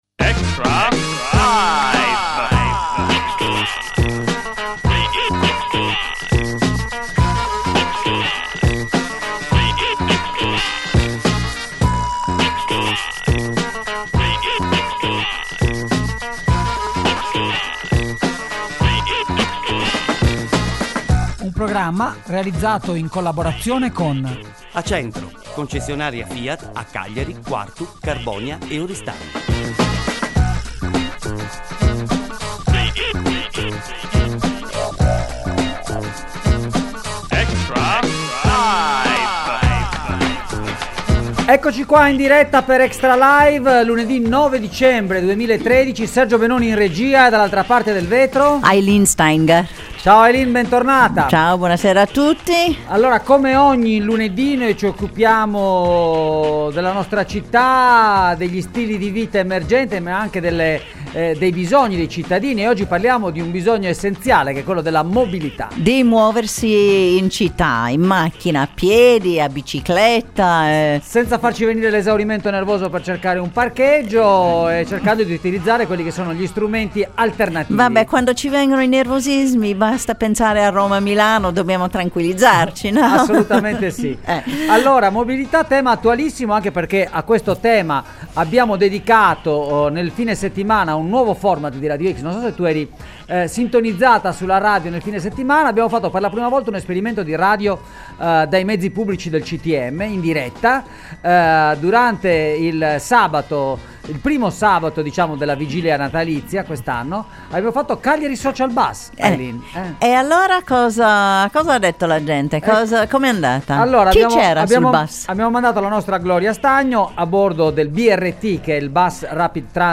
gli ospiti in studio
in collegamento telefonico dagli USA